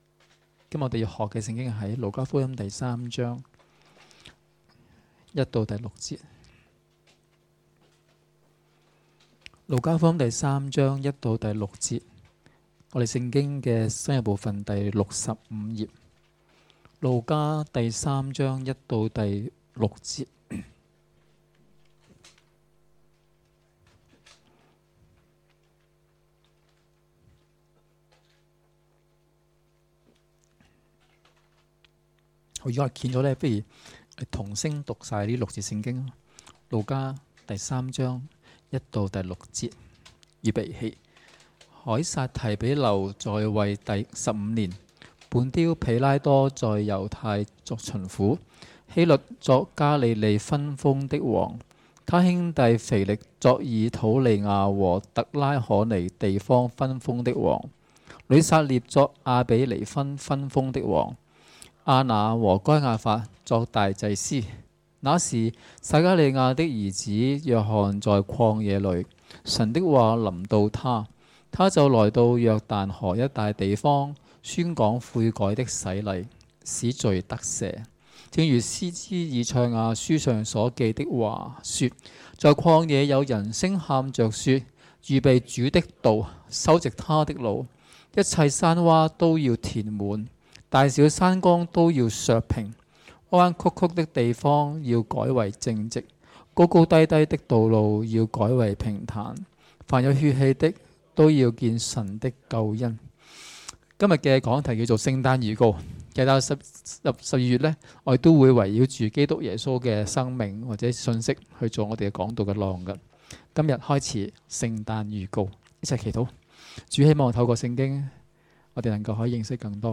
2016年12月3日及4日崇拜講道